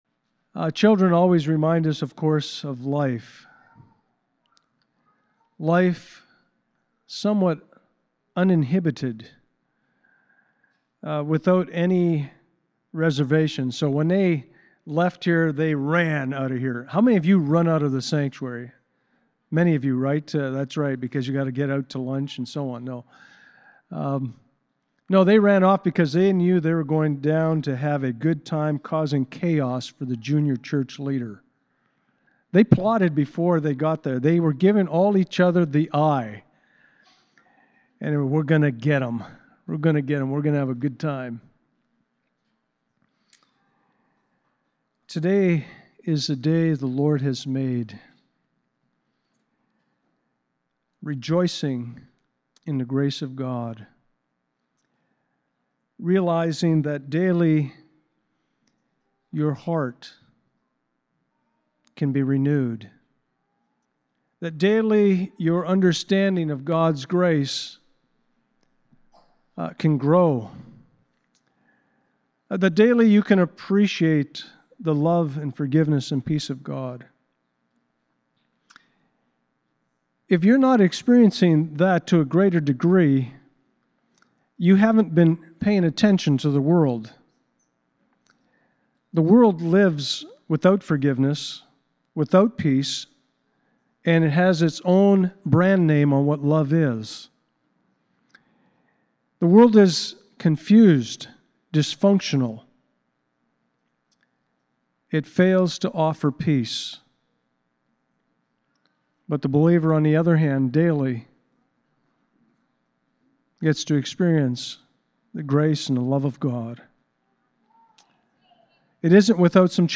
Passage: 2 Corinthians 4:7-18 Service Type: Sunday Morning